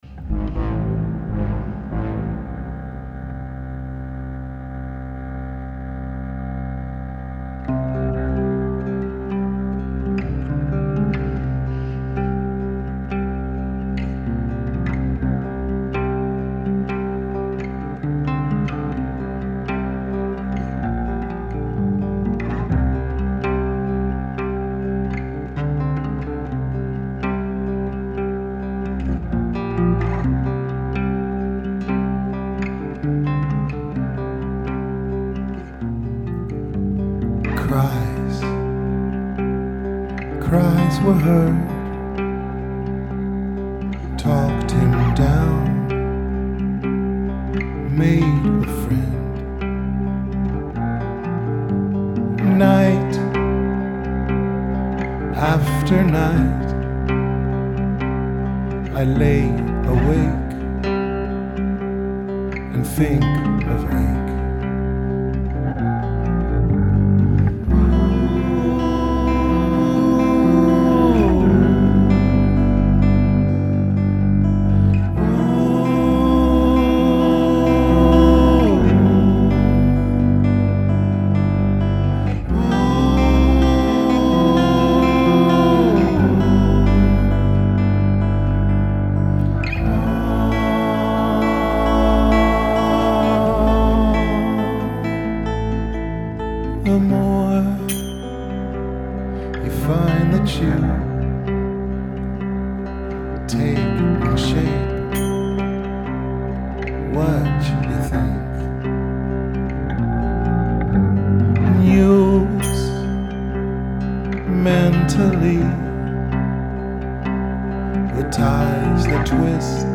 Rehearsals 3.3.2012